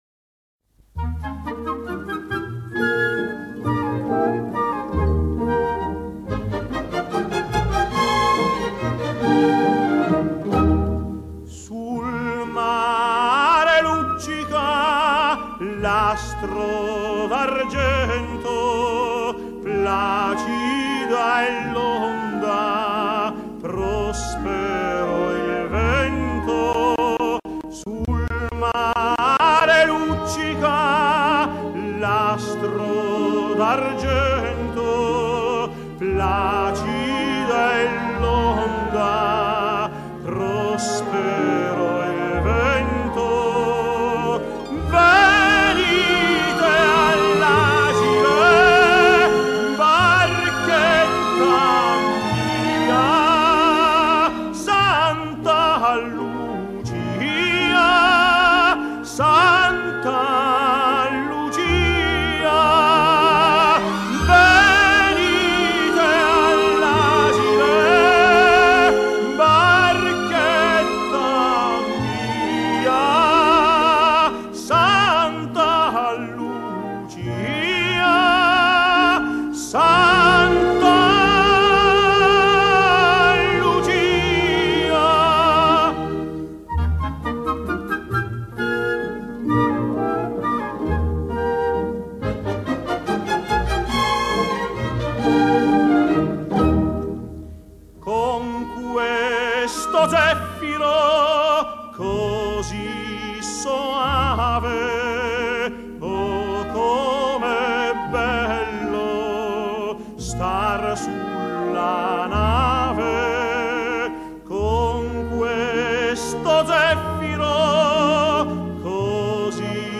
[2006-10-14]意大利歌曲——《桑塔.露齐亚 》
斯台法诺演唱：